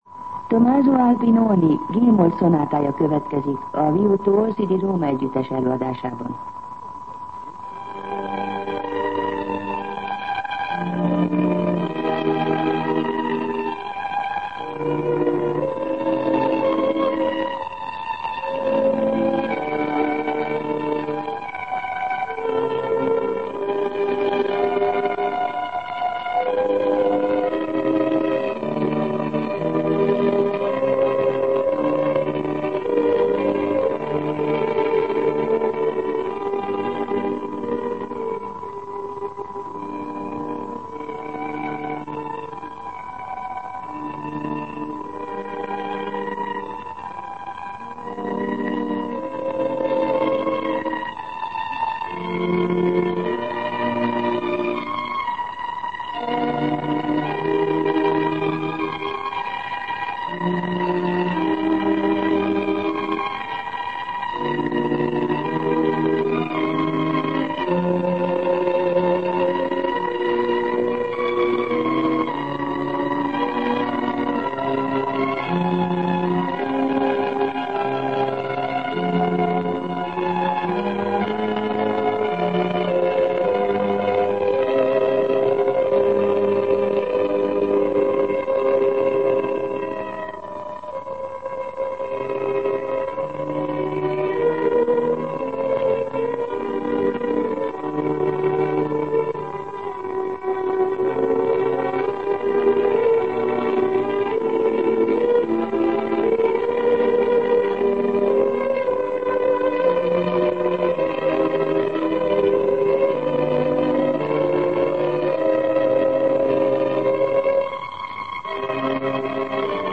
Zene
G-moll szonátája
szonátáját